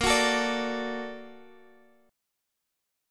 A#+M7 chord